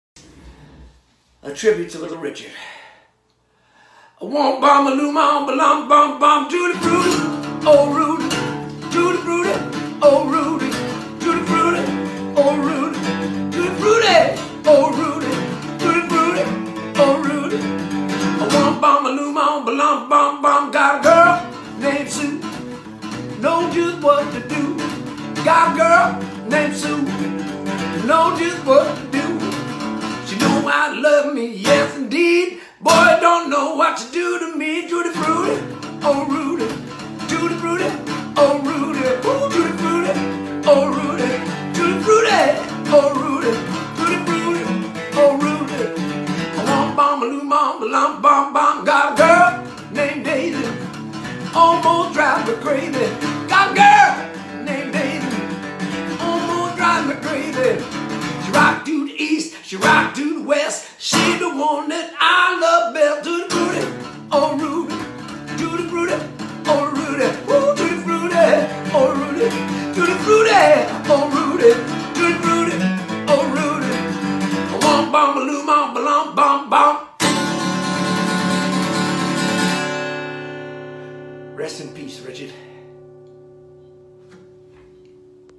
bei seinem Gesang lässt sich das nicht verleugnen